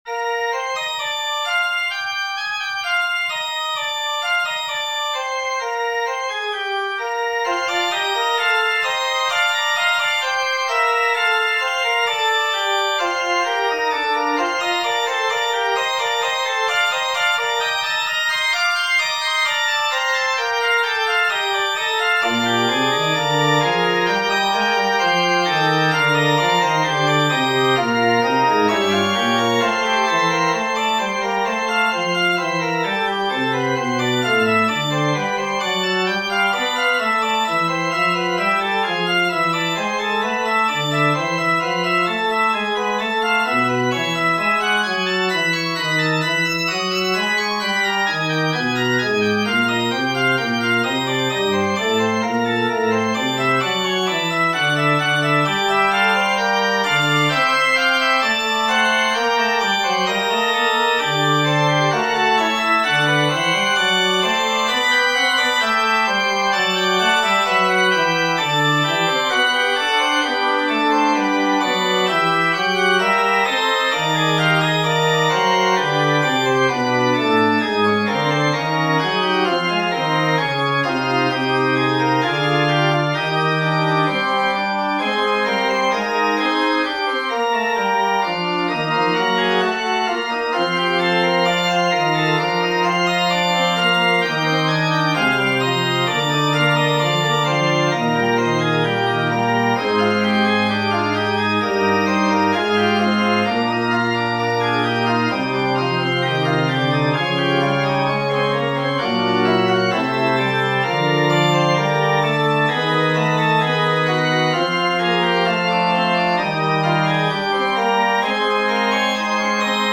This is a 3 voice fugue that I wrote to audition for a university.
Music / Classical